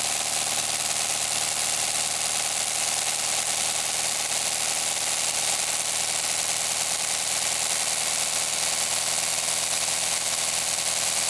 Depiladora eléctrica
Sonidos: Hogar